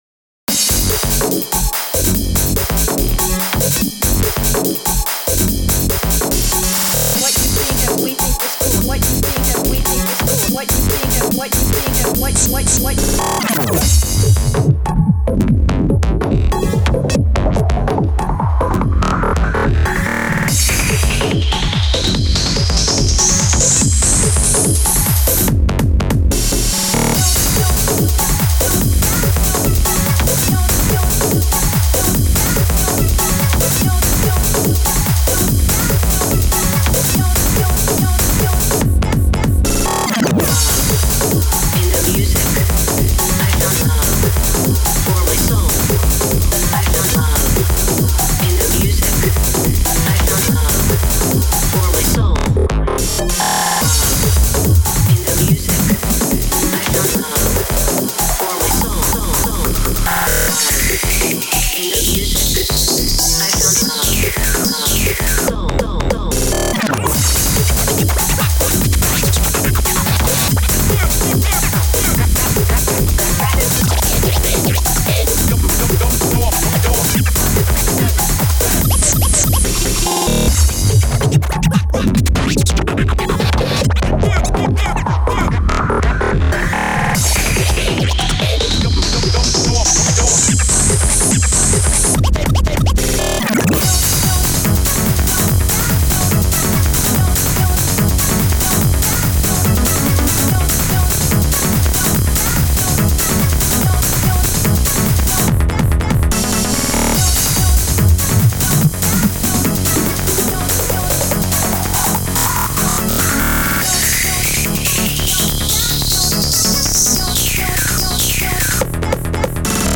Tech Dance